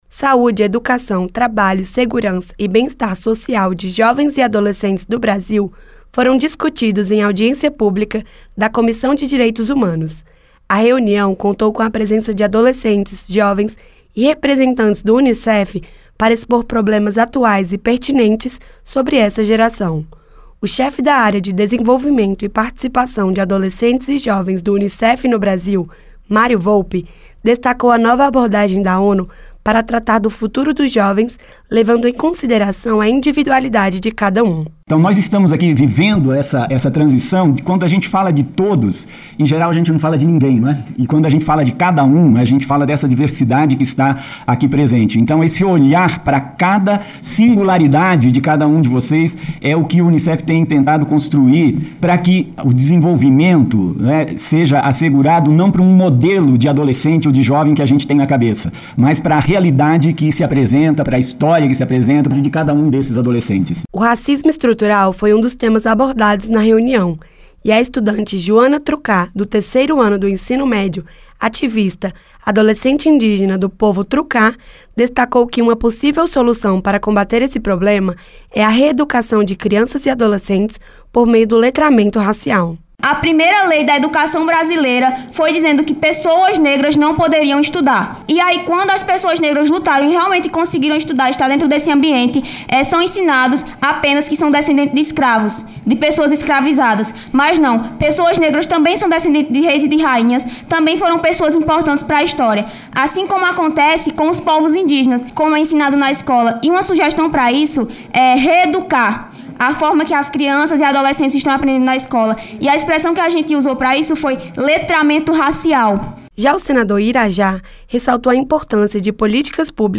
A Comissão de Direitos Humanos (CDH) debateu em audiência pública, o futuro dos adolescentes e dos jovens do Brasil. A reunião contou com a participação de representantes dos quilombolas, dos indígenas, das mulheres, dos transexuais e do Fundo das Nações Unidas para a Infância (UNICEF). Debateram assuntos como saúde, educação, trabalho e bem-estar.